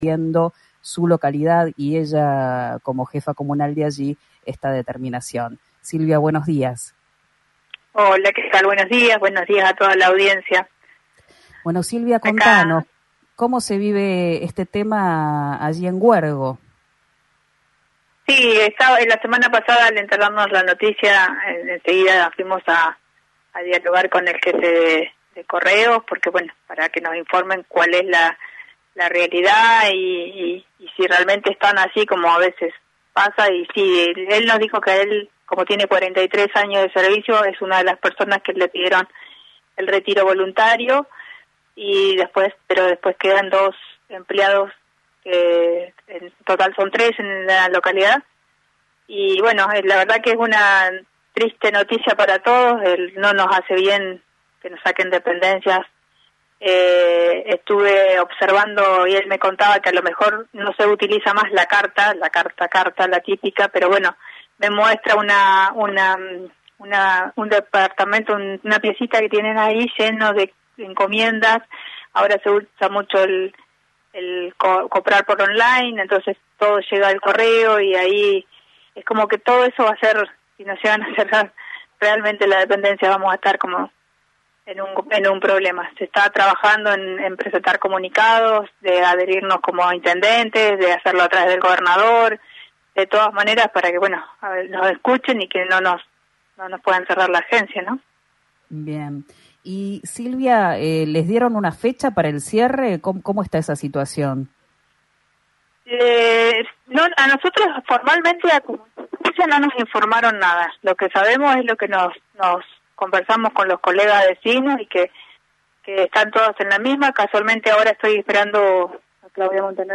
La intendenta Silvia Penilla habló este lunes con RÍO NEGRO RADIO y reveló detalles sobre el cronograma aniversario.